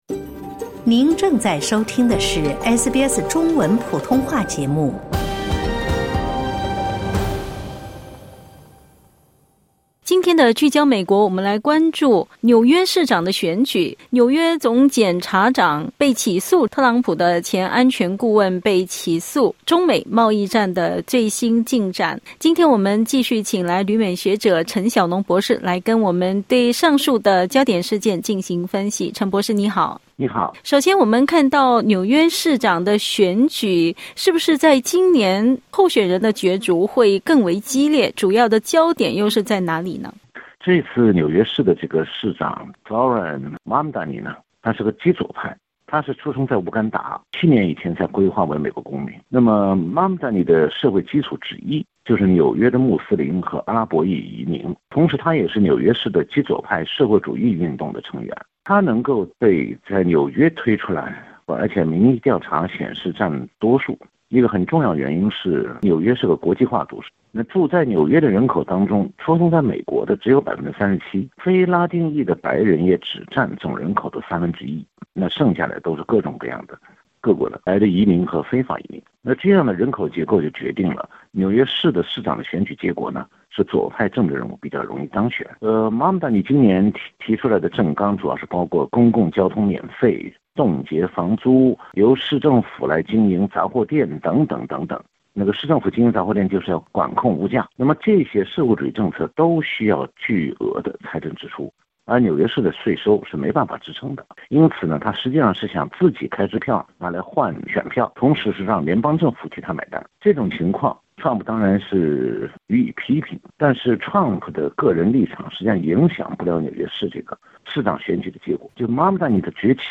点击音频收听详细内容 采访内容仅为嘉宾观点 欢迎下载应用程序SBS Audio，订阅Mandarin。